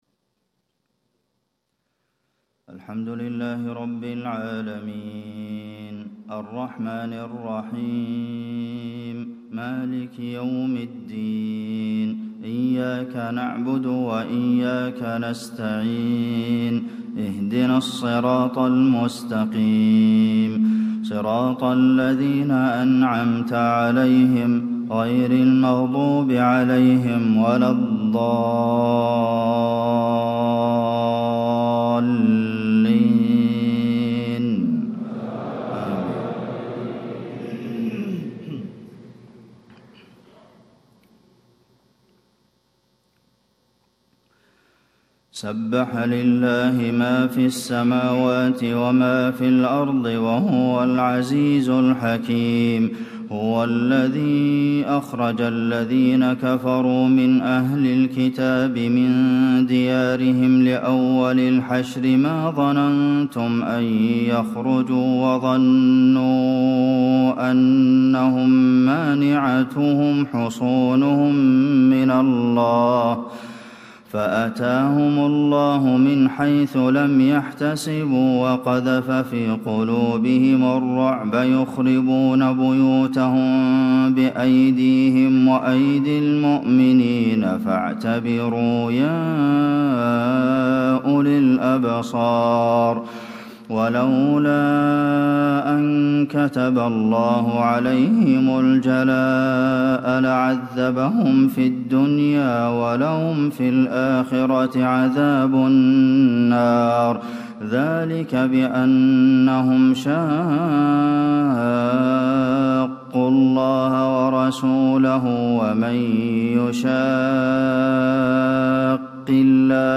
صلاة الفجر 24 محرم 1437هـ فواتح سورة الحشر 1-17 > 1437 🕌 > الفروض - تلاوات الحرمين